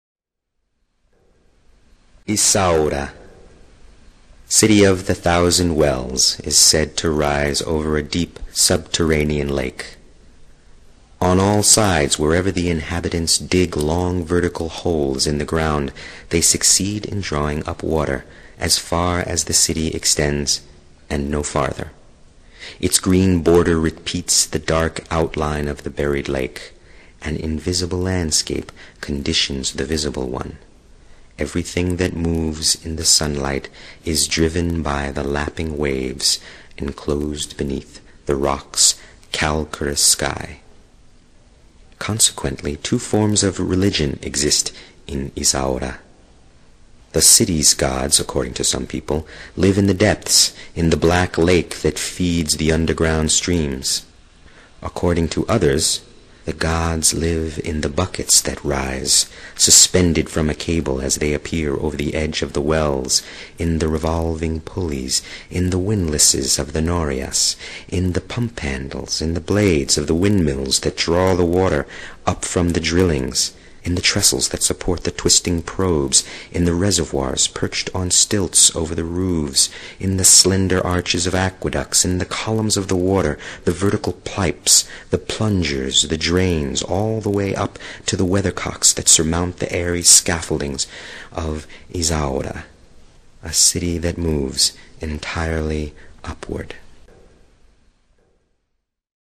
An Italian Accent
Invisible Cities by Italo Calvino in the voice of a native
El orador es nativo de Torino, Italia, y se observa un fuerte acento italiano en su pronunciación.